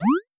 Jump3.wav